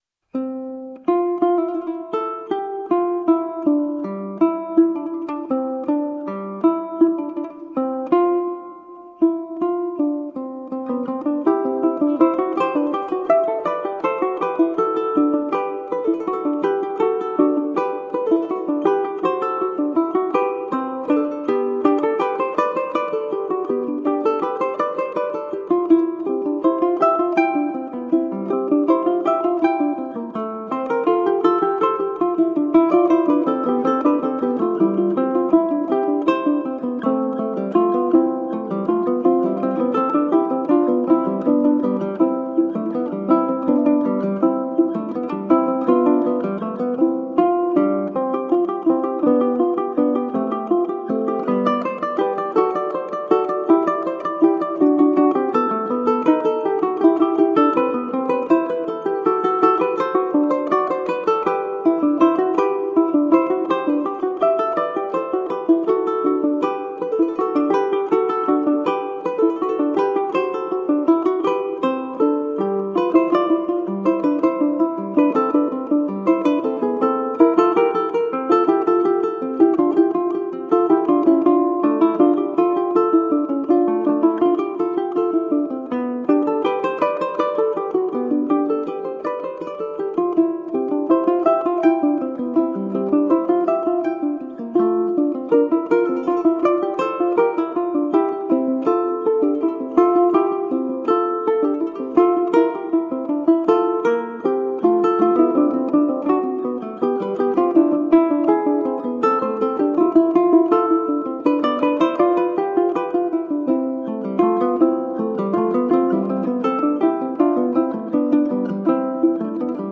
Colorful classical guitar.
Classical, Baroque, Instrumental